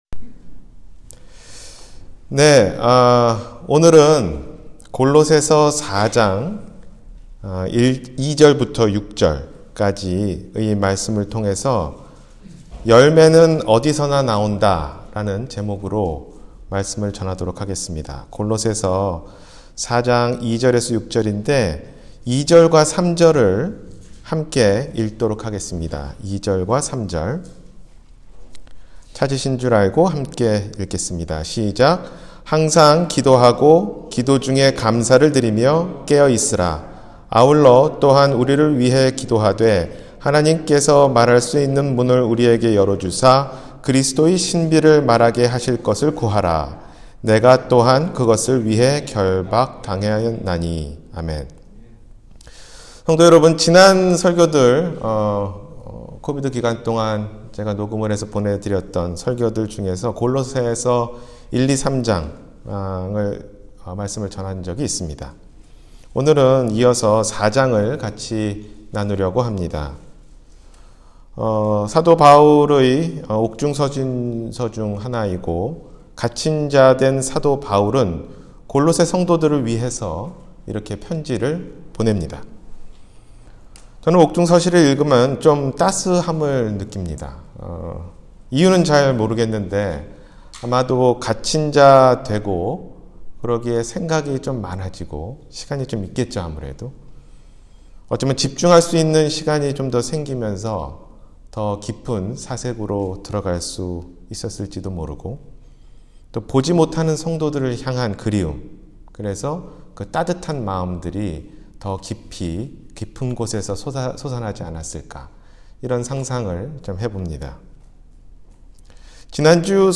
열매는 어디서나 나온다 – 주일설교